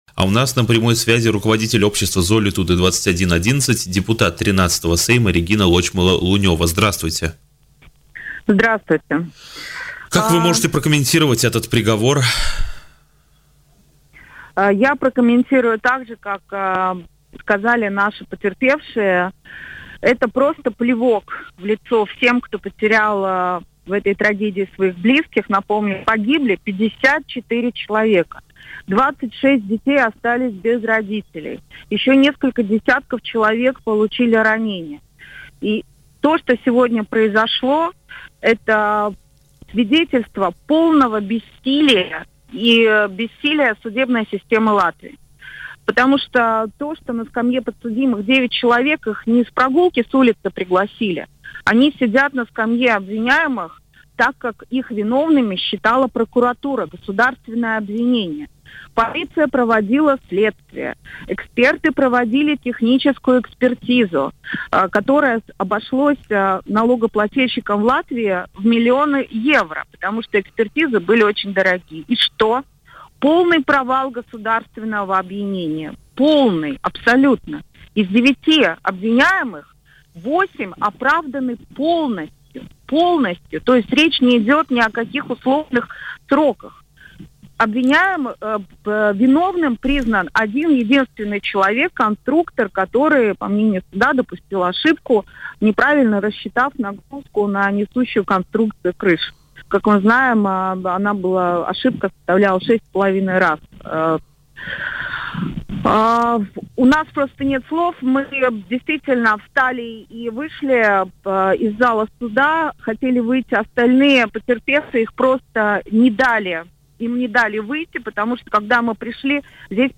Оправдательный вердикт суда в отношении 8 подсудимых по делу о золитудской трагедии – это плевок в лицо пострадавших и провал судебной системы Латвии, об этом в эфире радио Baltkom сказала сразу после оглашения приговора руководитель общества «Золитуде 21.11», политик «Согласия» Регина Лочмеле-Лунева.